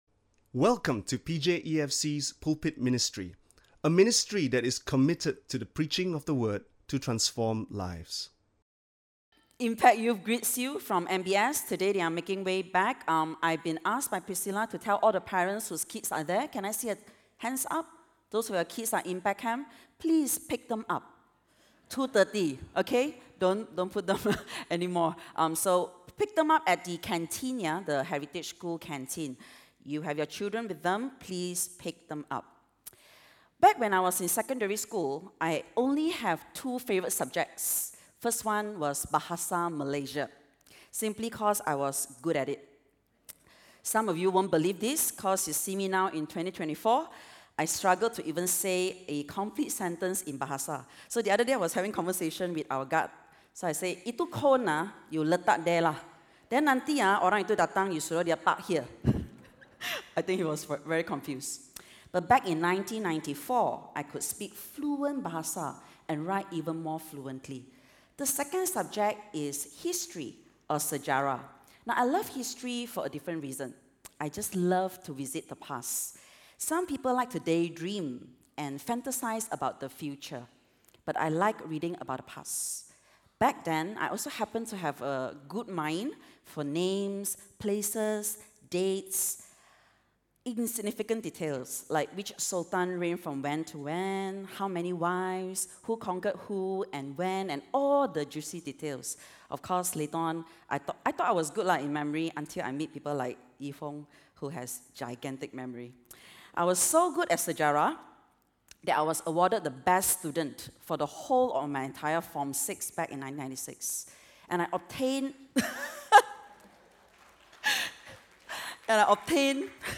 Listen to Sermon Only